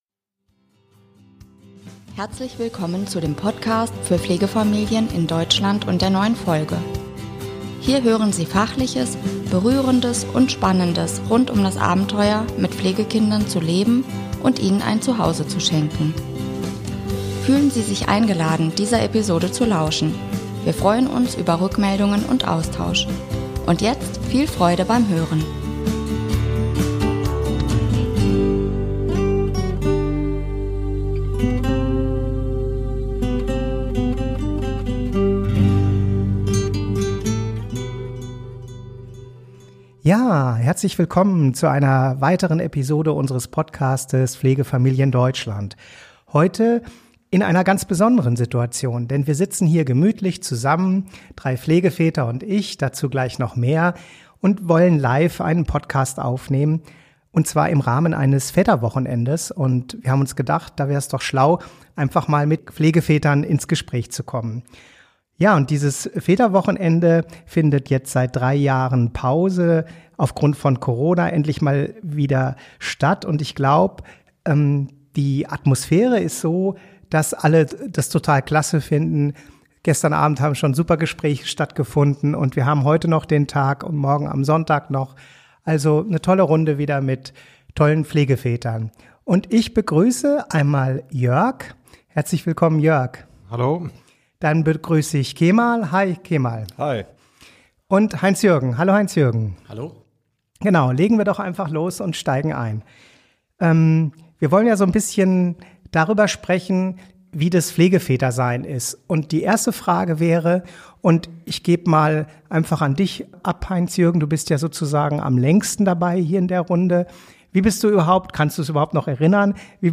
Heute haben wir 3 Pflegeväter eingeladen.
Also eine Live-Aufnahme in der Nähe des Edersees beim Auftanken für Pflegeväter. Wir wollen heute über ihre Geschichte, die Freude am Pflegefamilie sein und die besonderen Herausforderungen sprechen.